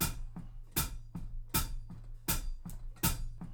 GROOVE 6 05L.wav